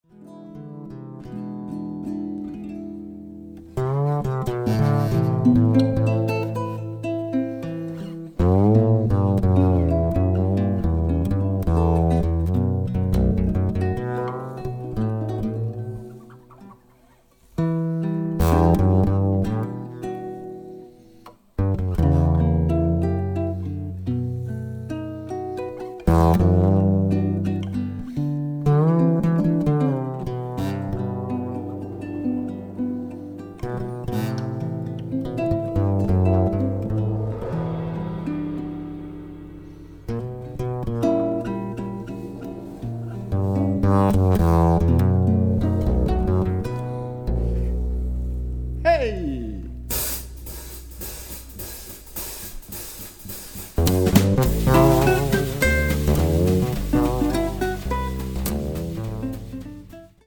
bass (solo)
guitar
drums
The whole without any special effect.